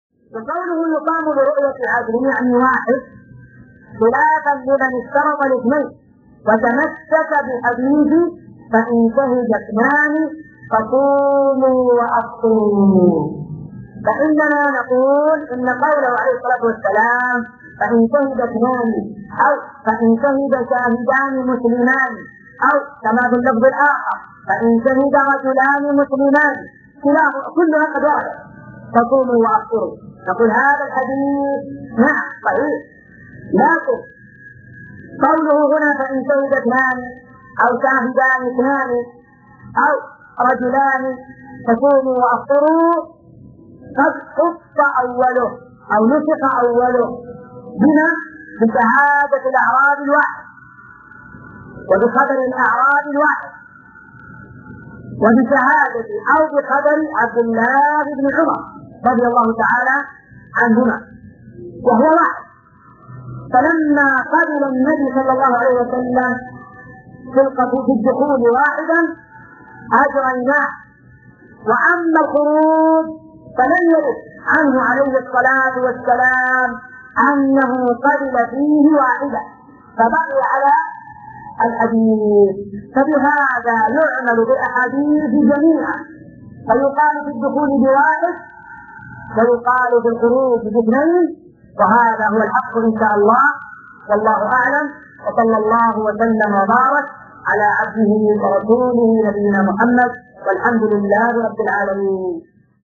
(مقتطف من شرح زاد المستقنع).